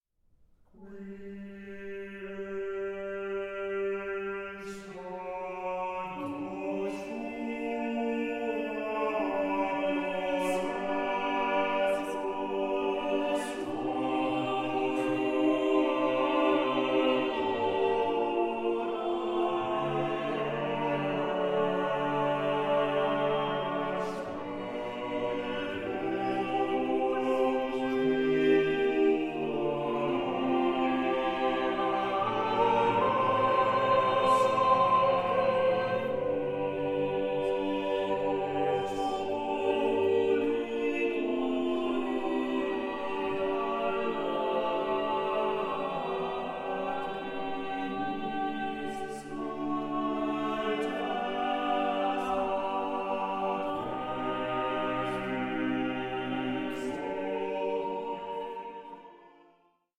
Passion-related motets